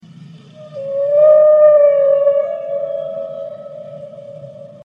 Протяжный вой кашалота